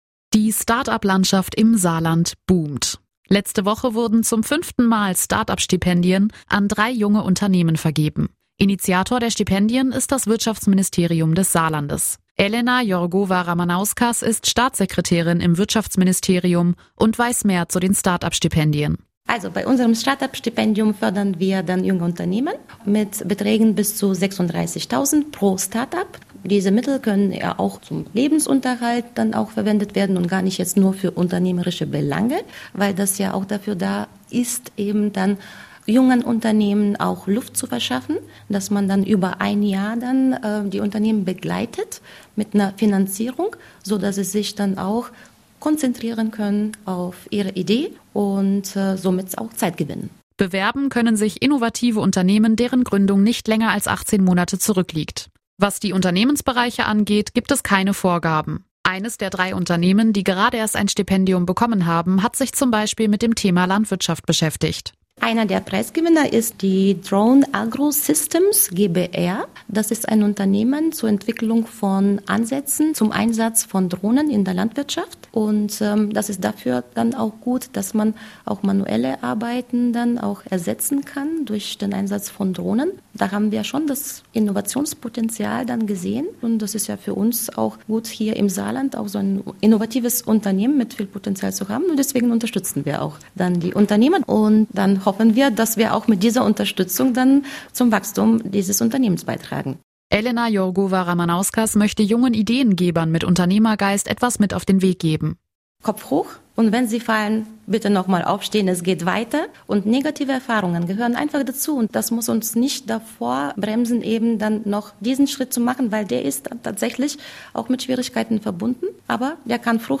spricht mit der Staatssekretärin im Wirtschaftsministerium